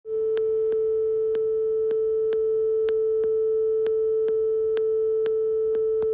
A few clicks every second